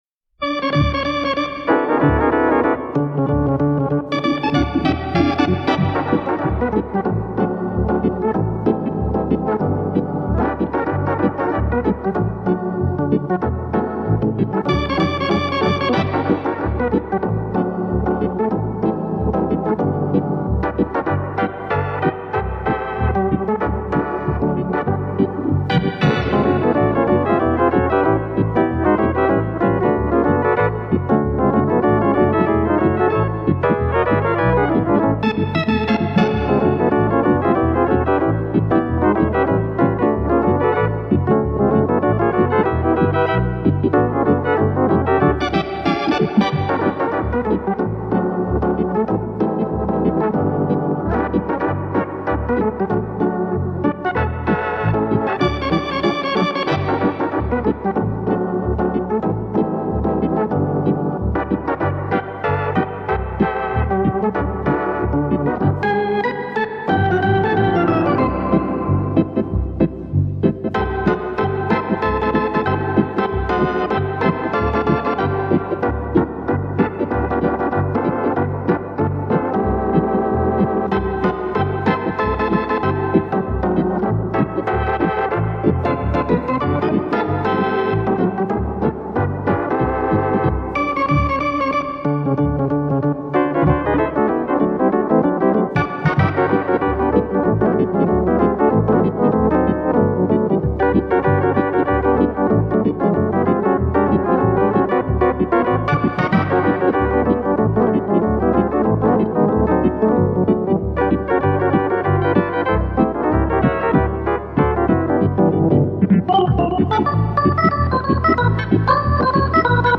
由于使用的比较老式电子琴的缘故，整个乐曲听起来更像风琴演奏的。显得很古老很古老，希望大家喜欢这个风格的音乐。